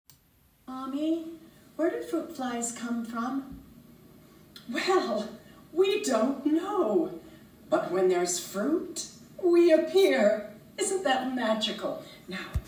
Her style is vibrant, fun, and heartfelt.